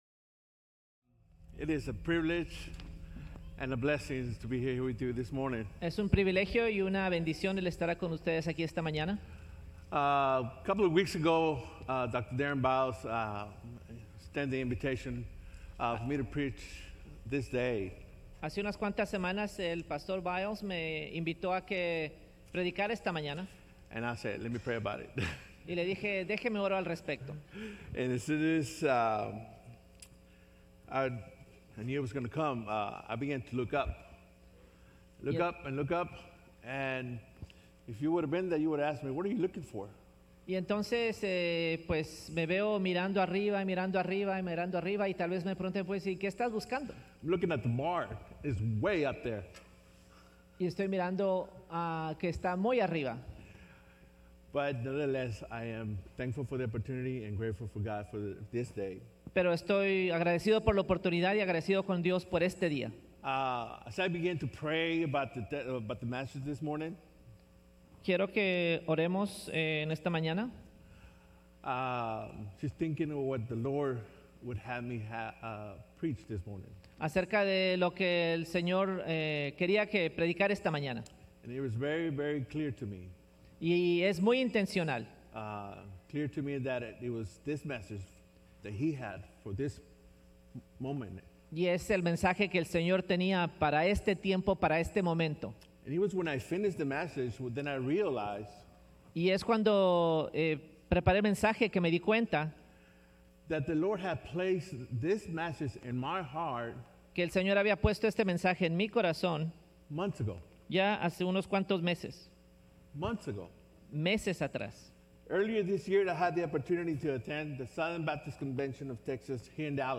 Sermons - Sunnyvale FBC
From Series: "Guest Speaker"